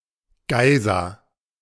Geisa (German pronunciation: [ˈɡaɪza]
De-Geisa.ogg.mp3